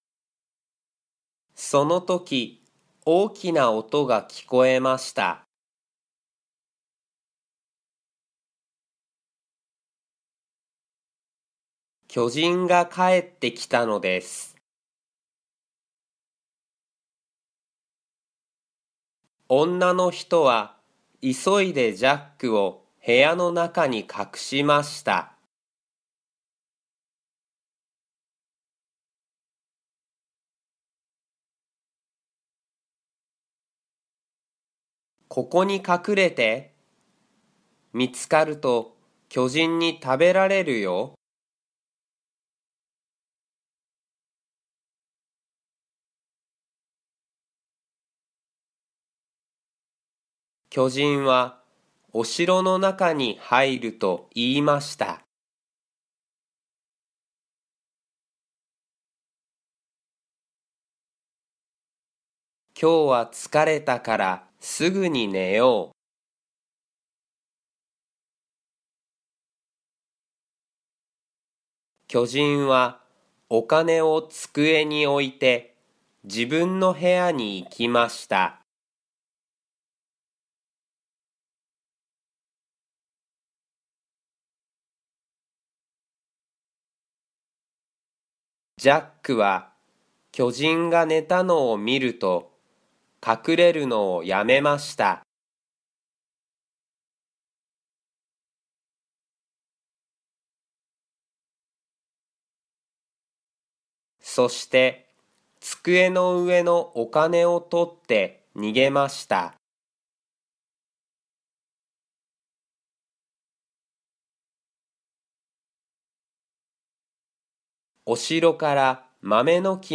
Japanese Graded Readers: Fairy Tales and Short Stories with Read-aloud Method
Slow Speed with Pauses